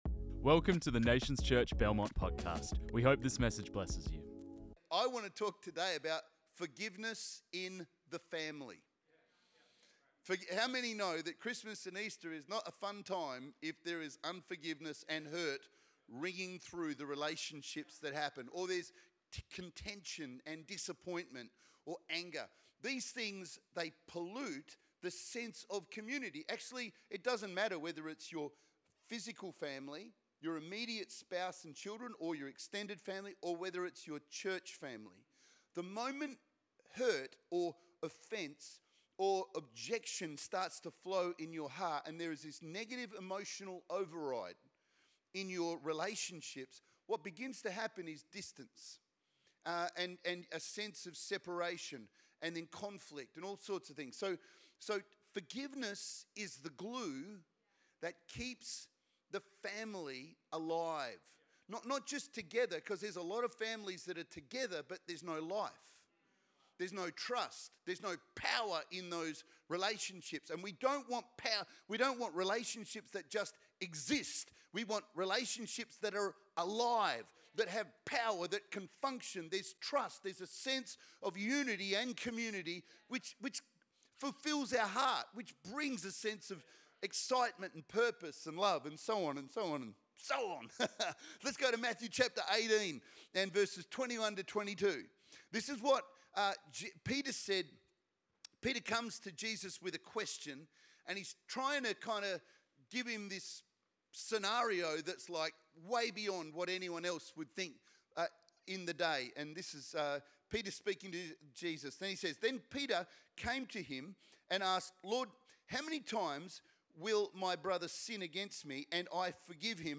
This message was preached on 17 November 2024.